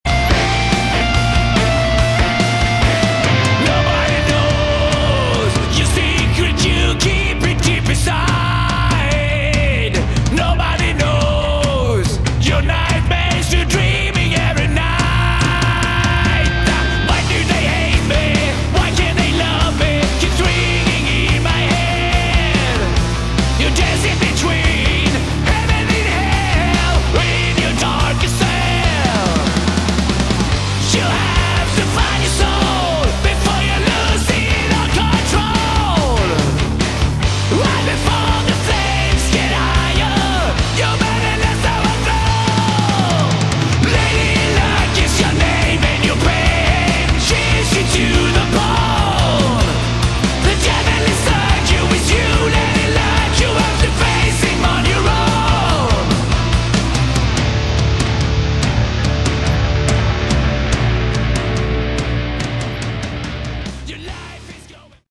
Category: Hard Rock
lead vocals, rhythm guitar
bass, backing vocals
drums, backing vocals
lead guitar, backing vocals